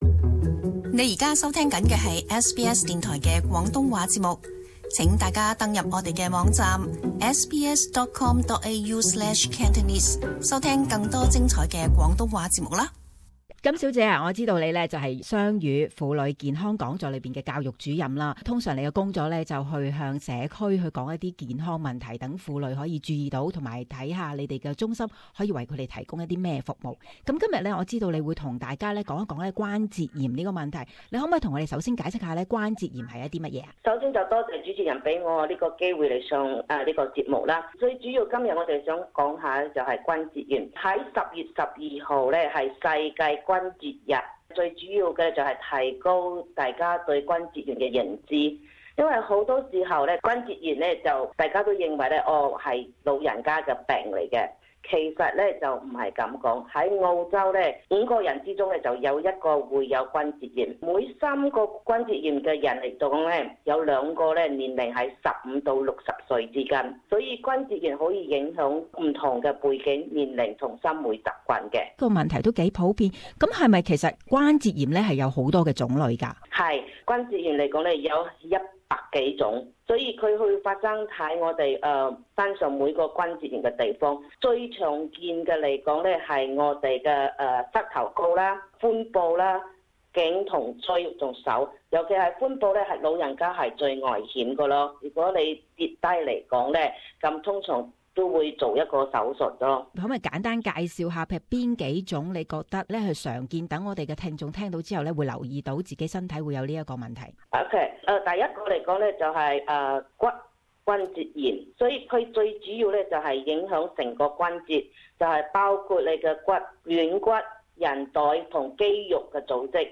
Community Interview: Multicultural Centre for Woman's Health